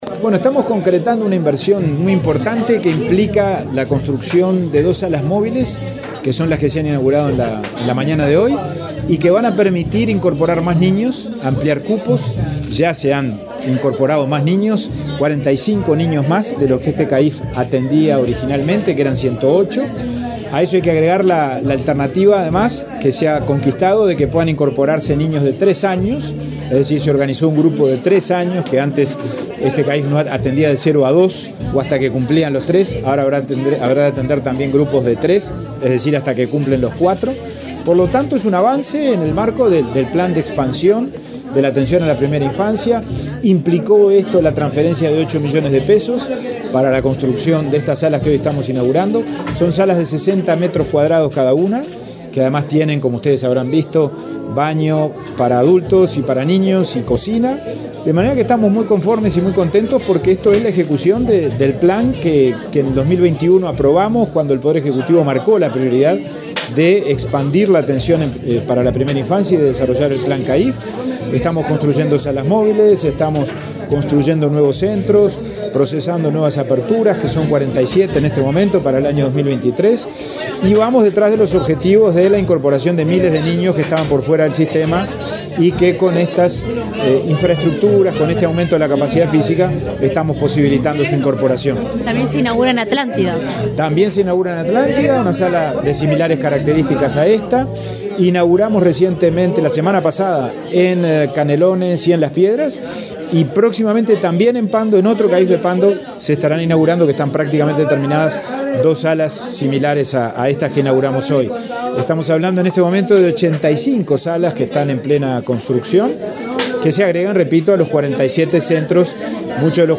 Declaraciones del presidente de INAU, Pablo Abdala
Declaraciones del presidente de INAU, Pablo Abdala 07/08/2023 Compartir Facebook X Copiar enlace WhatsApp LinkedIn El presidente de Instituto del Niño y el Adolescente del Uruguay (INAU), Pablo Abdala, dialogó con la prensa en Canelones, durante la inauguración de dos salas móviles en el CAIF de Pando y una en el de Estación Atlántida.